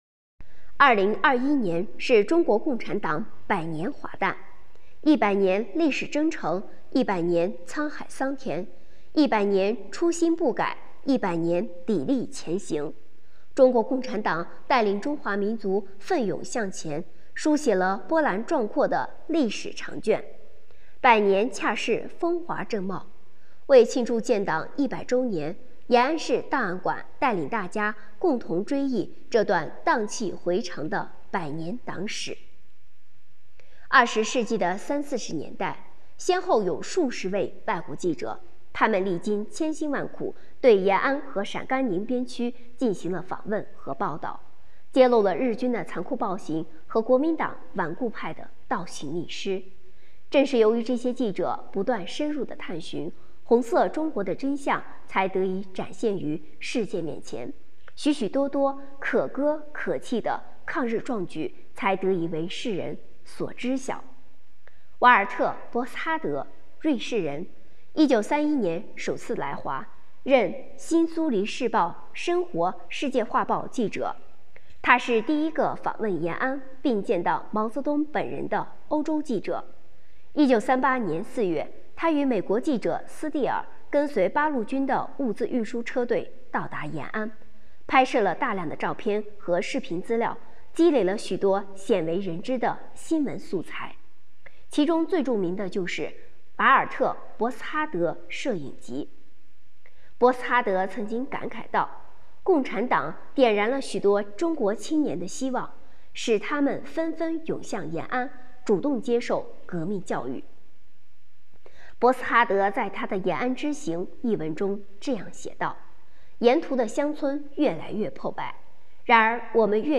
【红色档案诵读展播】第一个到达延安的欧洲记者——瓦尔特·博斯哈德